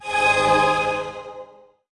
Media:RA_Battle Healer_Evo.wav UI音效 RA 在角色详情页面点击初级、经典和高手形态选项卡触发的音效